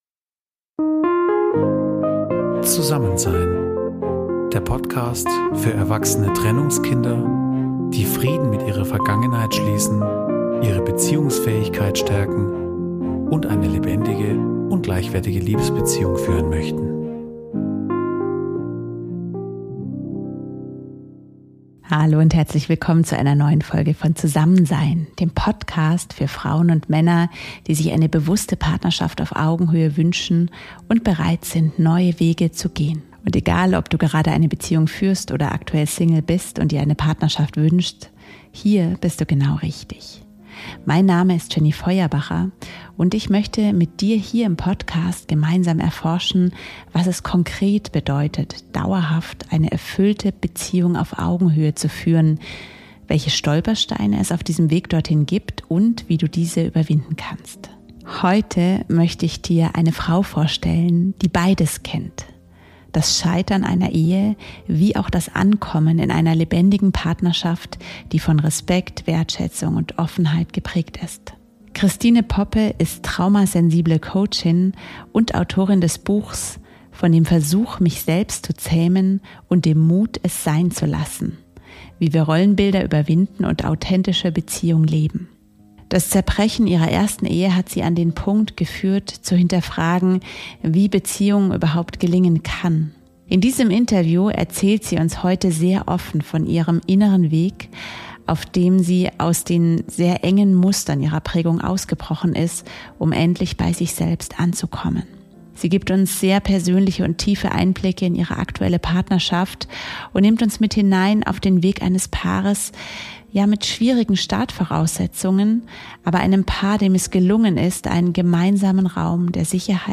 Wir sprechen dabei über: - Achtsame Gesprächsführung - Umgang mit Wut - Konfliktlösung auf Augenhöhe In diesem Interview steckt so viel Gold für dich und dein eigenes Beziehungsleben!